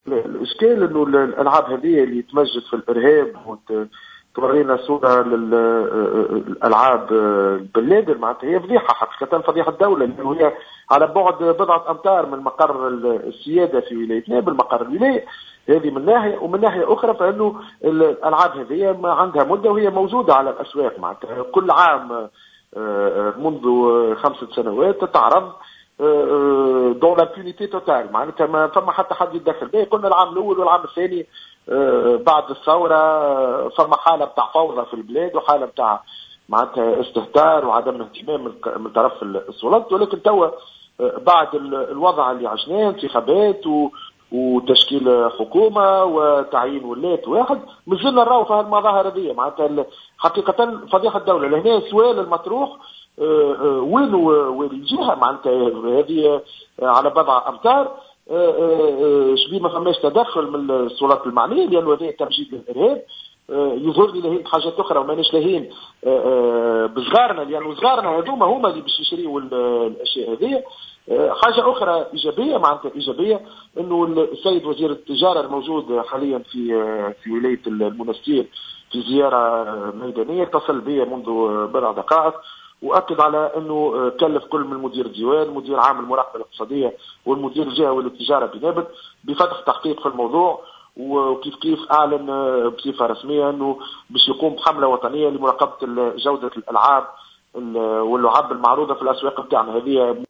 وأكد محمود الباردوي، النائب السابق بالمجلس التأسيسي في تصريح ل"الجوهرة أف أم" أنه تم عرض هذه الألعاب بالقرب من مقر ولاية نابل، واصفا الأمر بالفضيحة، وفق تعبيره.